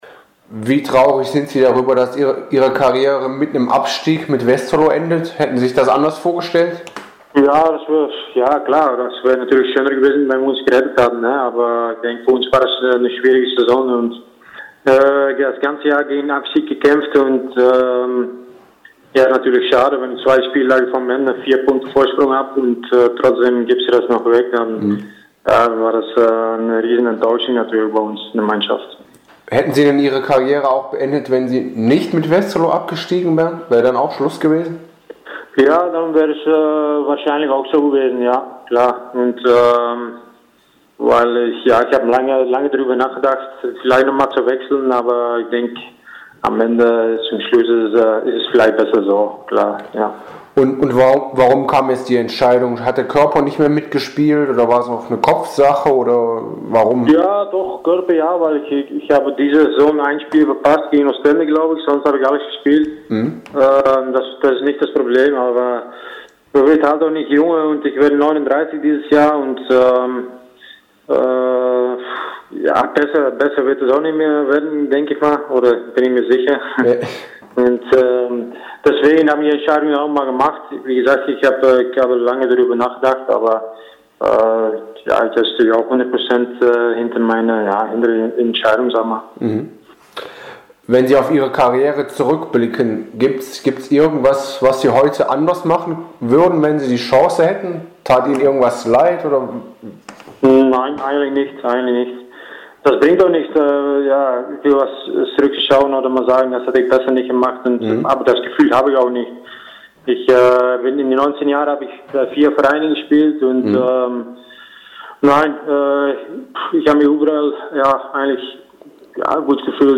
am Telefon